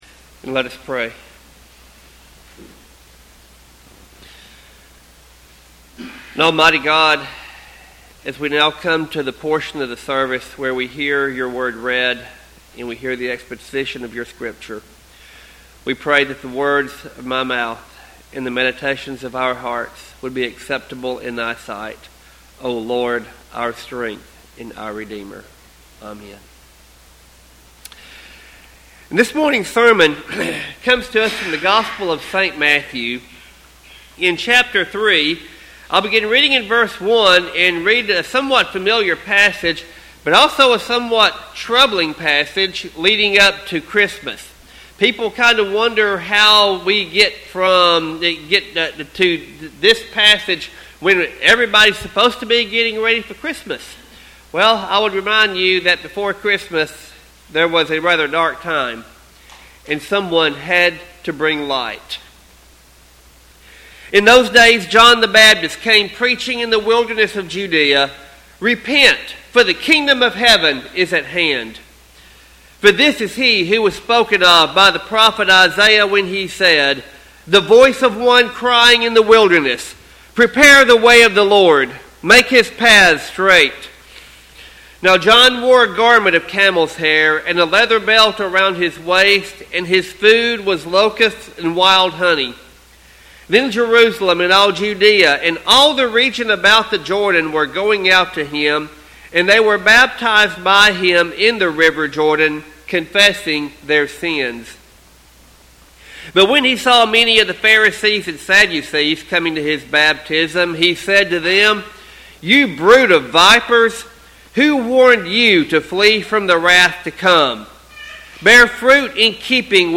Scripture reading: Psalm 72:1-7. Sermon text: Matthew 3:1-12.